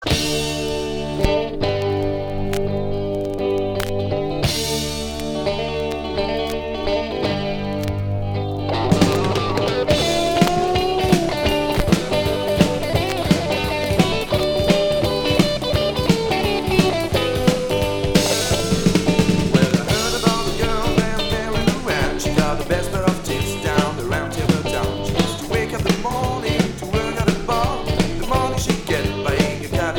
Rock sudiste